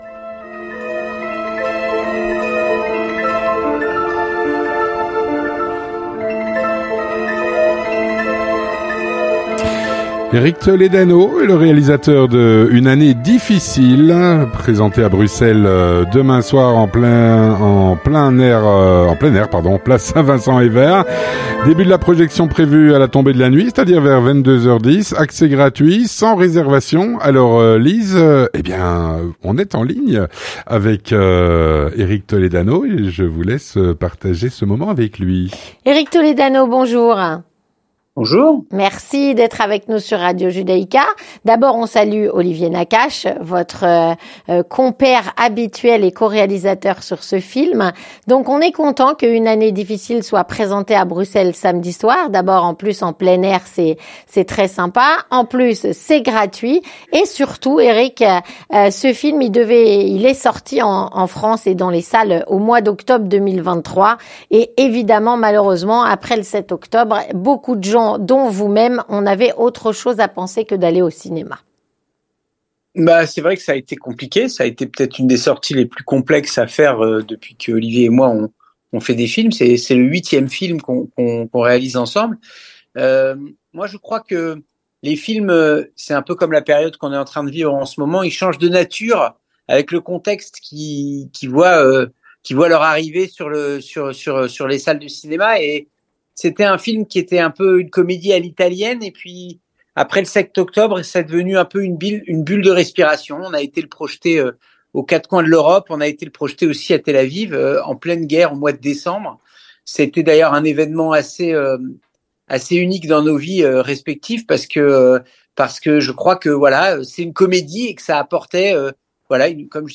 Avec Eric Toledano, réalisateur du film